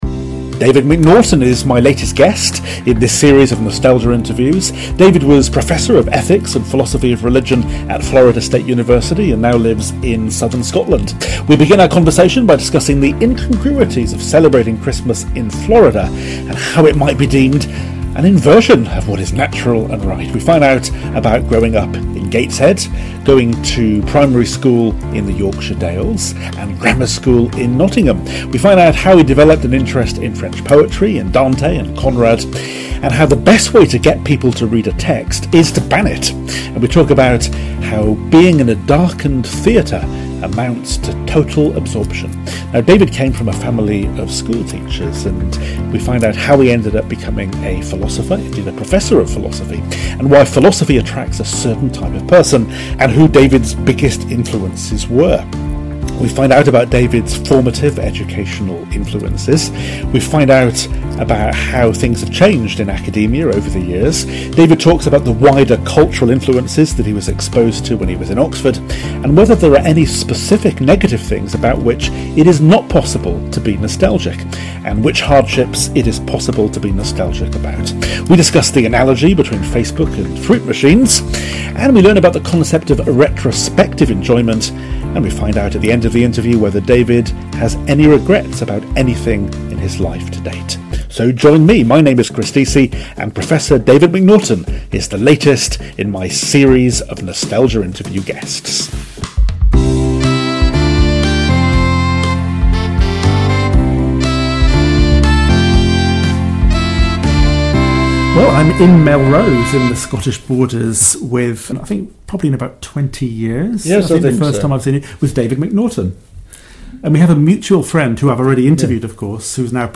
Nostalgia Interviews